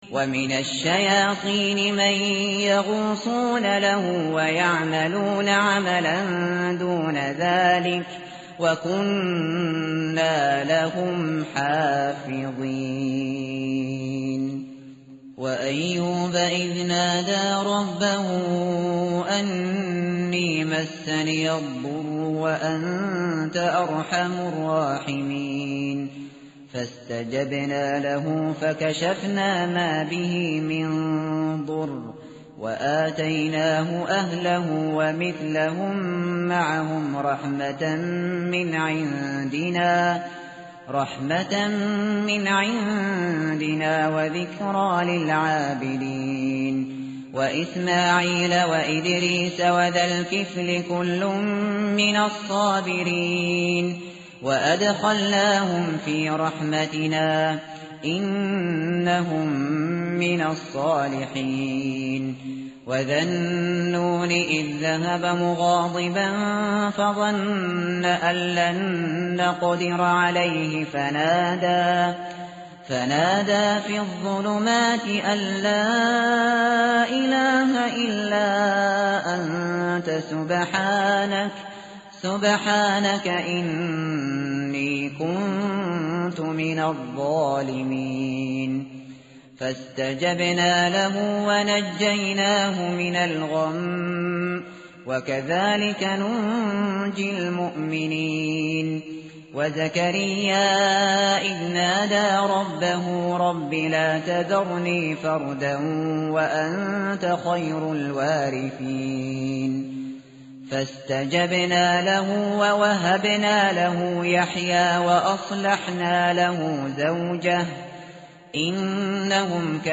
متن قرآن همراه باتلاوت قرآن و ترجمه
tartil_shateri_page_329.mp3